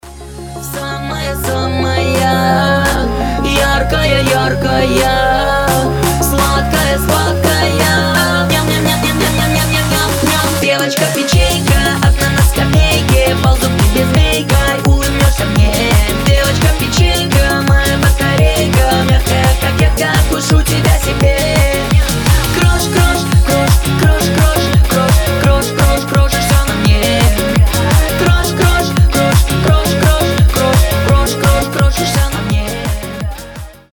• Качество: 320, Stereo
веселые
быстрые
смешные